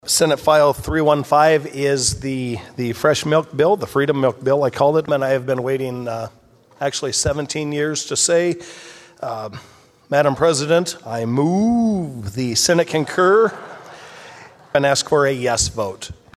Senator Jason Schultz, a Republican from Schleswig, celebrated last (Monday) night as the senate was on the verge of taking final action on the bill.